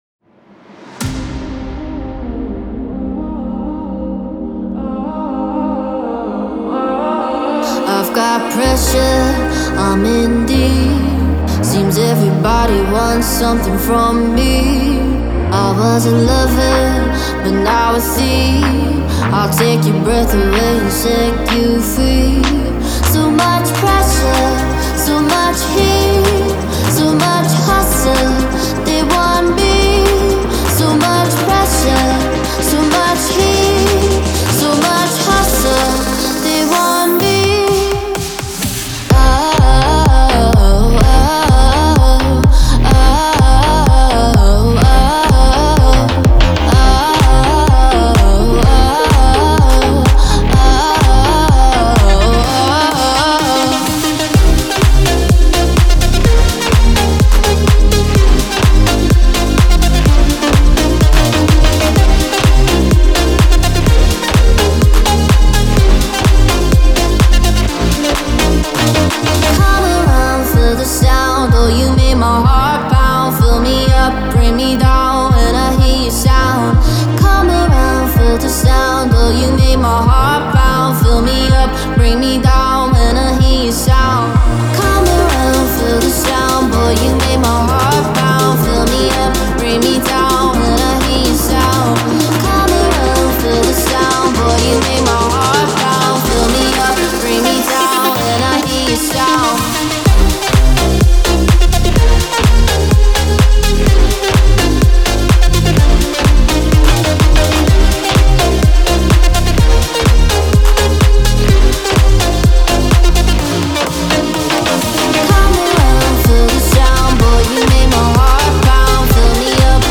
который сочетает в себе элементы EDM и поп-музыки.